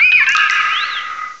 cry_not_manaphy.aif